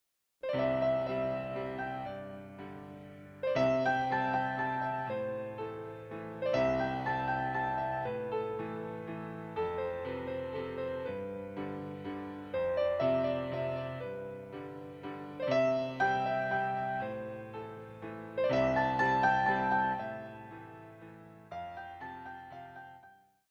34 Piano Selections